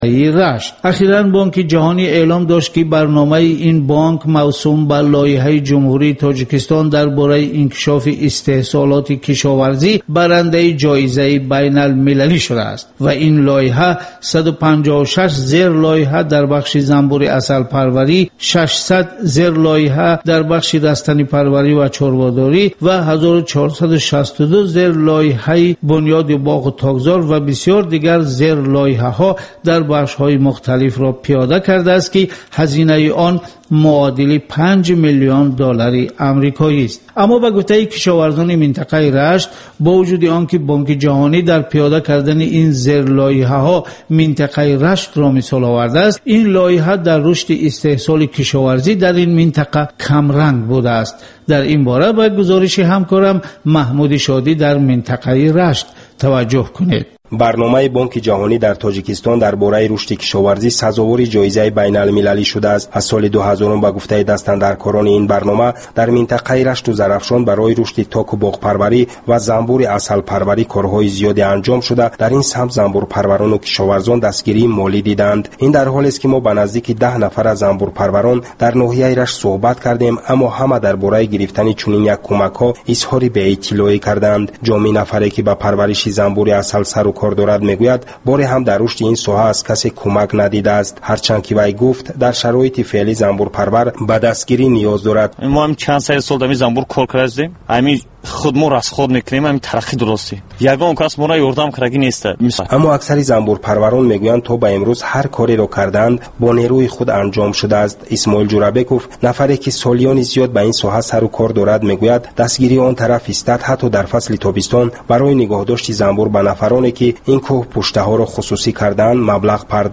"Як рӯз бо Шумо" барои дарки рӯзгори Шумо. Маҷаллаи ғайриодӣ, ки ҳамзамон дар шакли видео ва гуфтори радиоӣ омода мешавад.